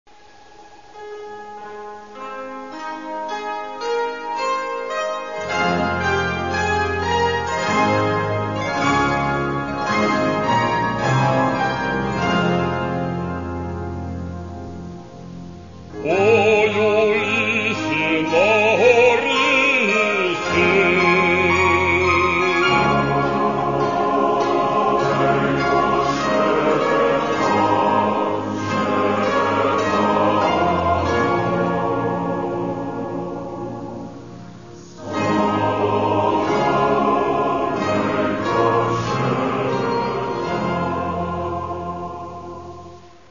Catalogue -> Folk -> Bandura, Kobza etc
lyrics: ukrainian folk song